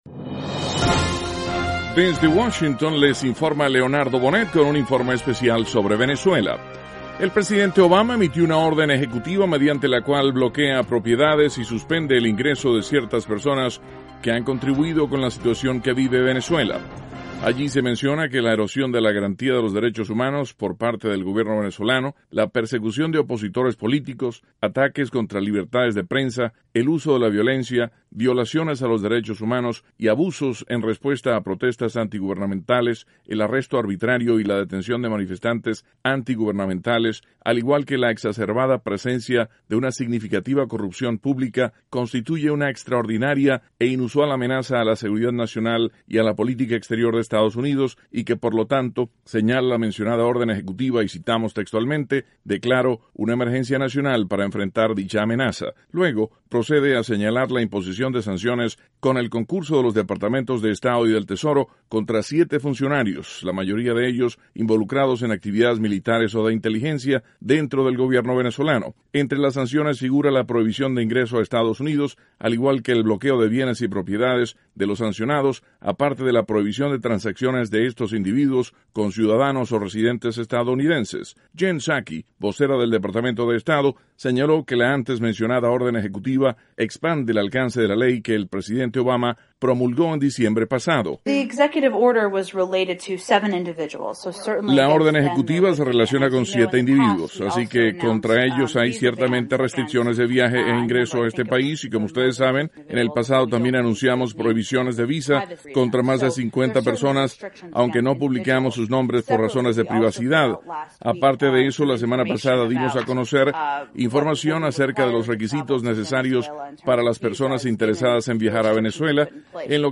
Noticias PM - Lunes, 9 de marzo, 2015